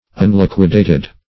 Search Result for " unliquidated" : The Collaborative International Dictionary of English v.0.48: Unliquidated \Un*liq"ui*da`ted\, a. Not liquidated; not exactly ascertained; not adjusted or settled.
unliquidated.mp3